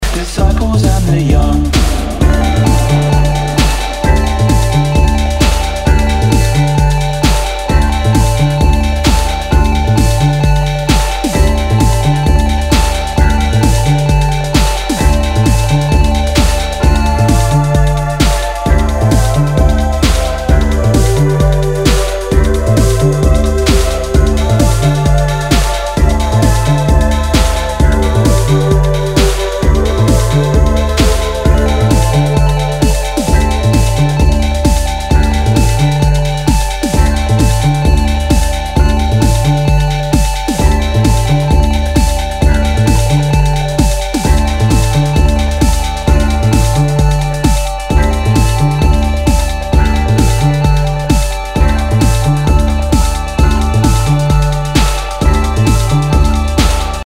HOUSE/TECHNO/ELECTRO
エレクトロ/ インディー・ロック！
ビニールヤケあり、全体にチリノイズが入ります
[VG-] 傷や擦れが目立ち、大きめなノイズが出る箇所有り。